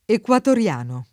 ecuadoriano [ekUadorL#no] (meno com. equadoriano [id.]; meno com. anche equadoregno [ekUador%n’n’o] e meno bene ecuadoregno [id.]; oggi raro equatoriano [